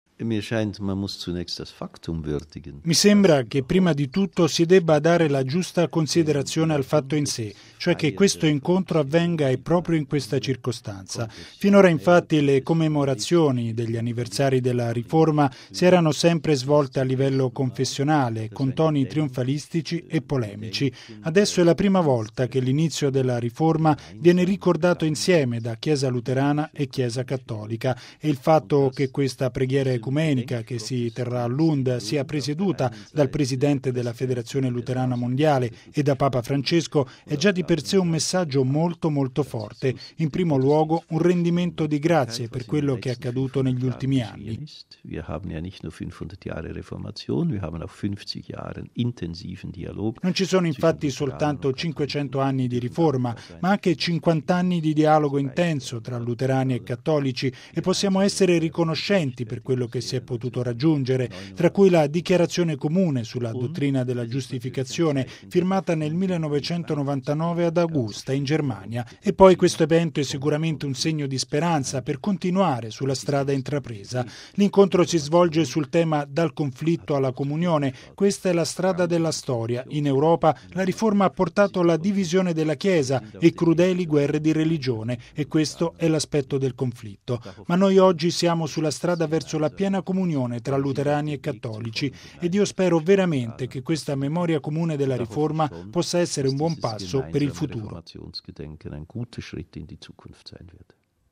Ascoltiamo le parole del porporato: